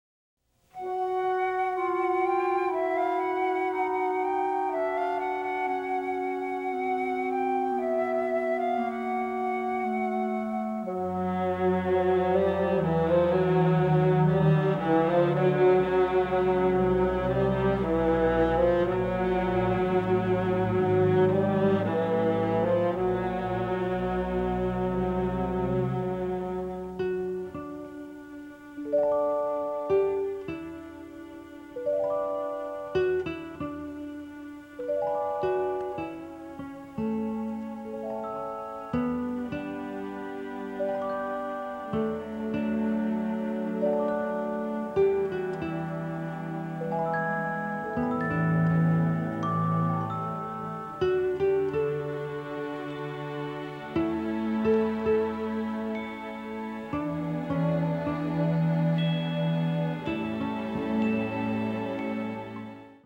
lush orchestral score